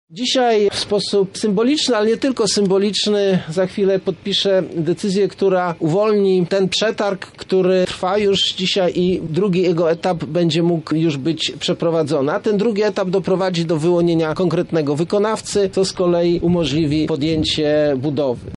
O inwestycjach mówi wiceminister infrastruktury, Jerzy Szmit